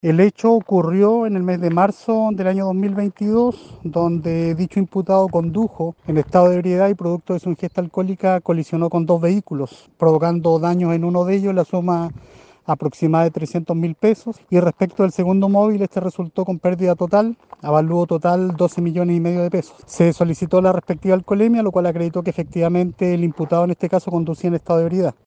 El fiscal Jaime Aguayo detalló que el hecho se registró en marzo de 2022, cuando el concejal colisionó contra dos vehículos y tras realizarle la alcoholemia se corroboró que estaba ebrio.